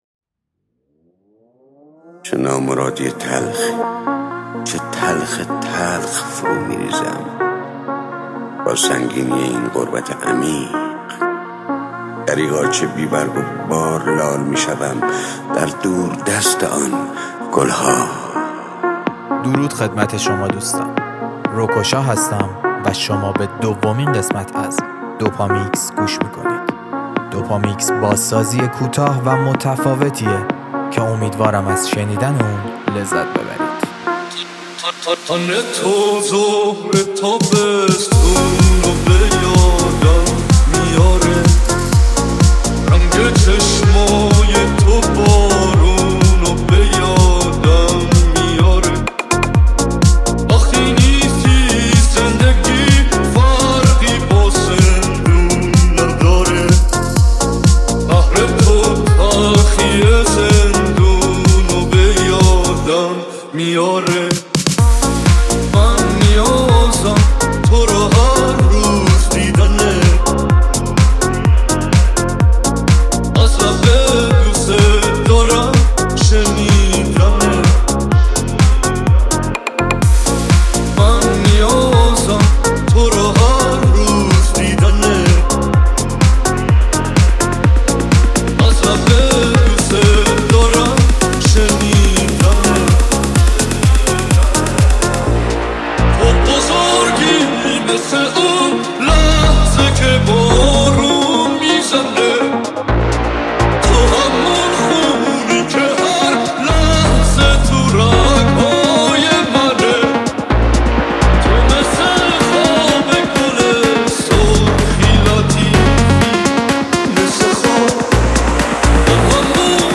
در سبک تکنو